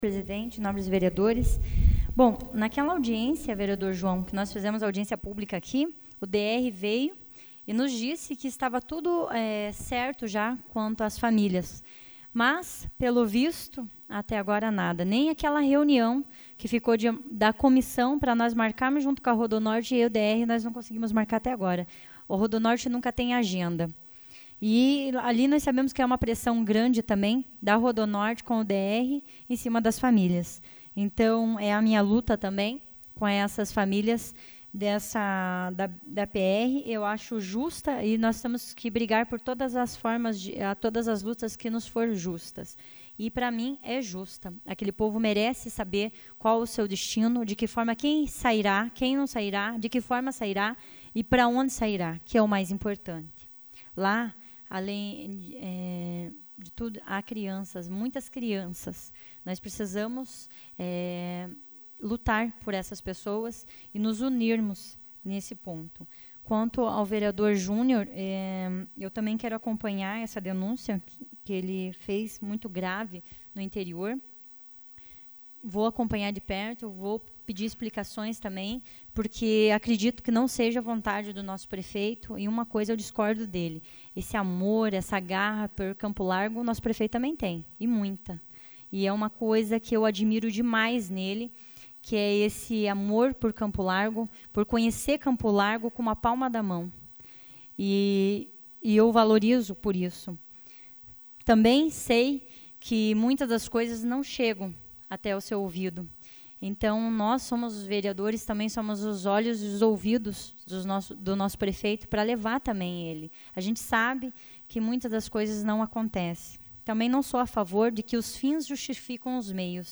SAPL - Câmara Municipal de Campo Largo - PR
Explicações pessoais AVULSO 08/04/2014 Fernanda do Nelsão